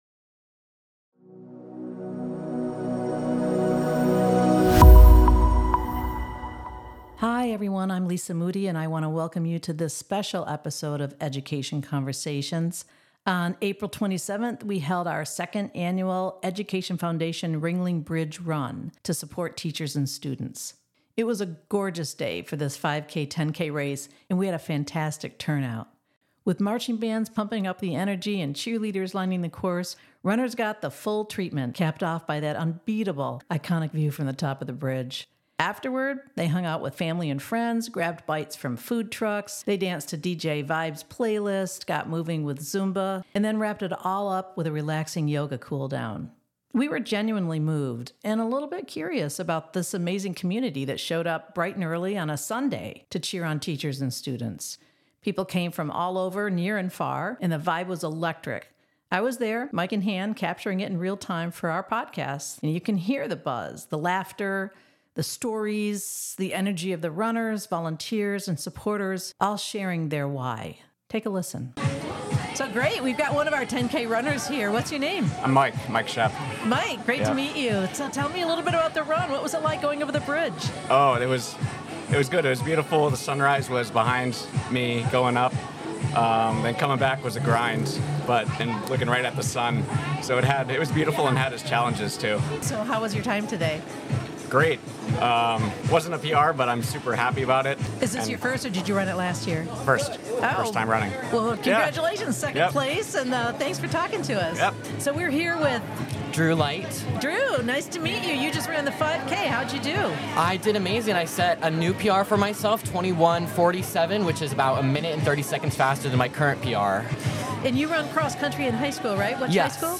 This special episode of Education Conversations captures the excitement and energy of the 2nd annual Education Foundation Ringling Bridge Run. Hear from runners, volunteers, and even celebrities who showed up for this 5K/10K race to support teachers and students.